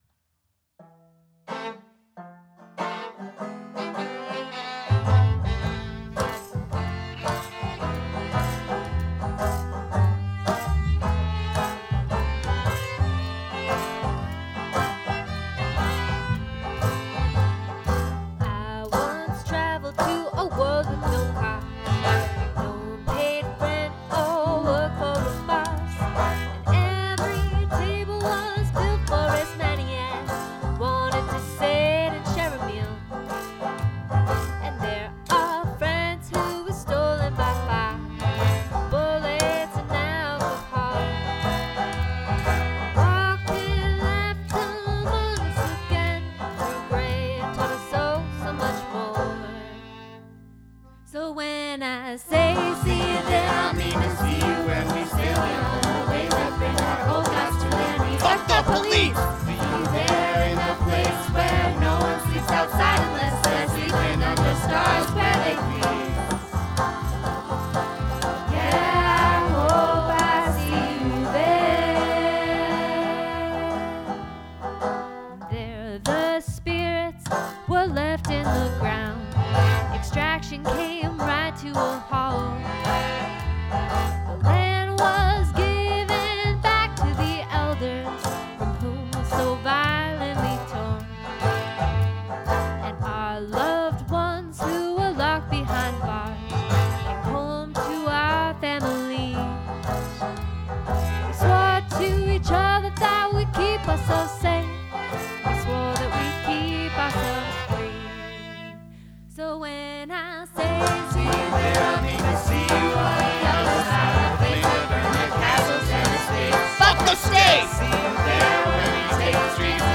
accordéon, banjo, violon et washboard
folk punk